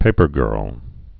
(pāpər-gûrl)